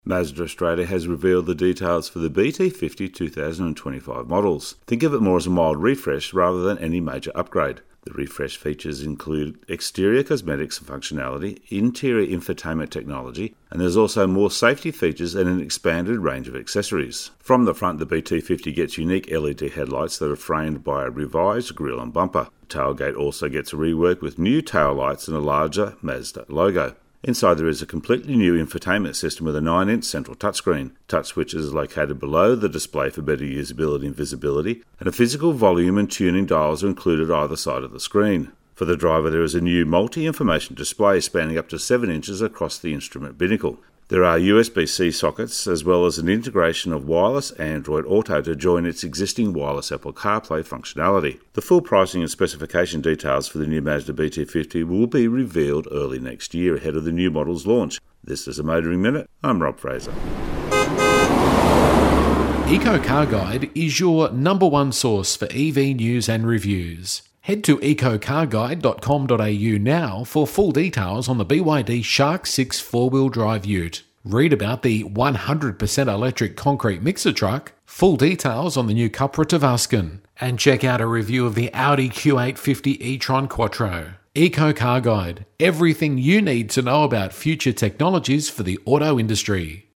Radio Segments Motoring Minute